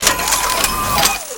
aim.wav